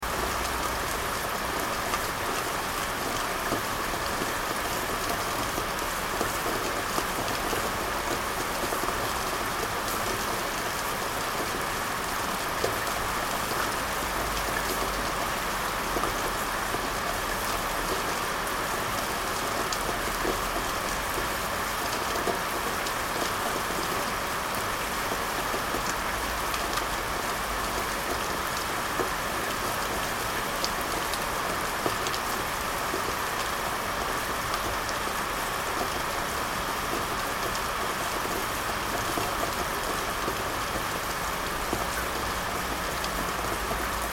دانلود آهنگ باران 7 از افکت صوتی طبیعت و محیط
دانلود صدای باران 7 از ساعد نیوز با لینک مستقیم و کیفیت بالا
جلوه های صوتی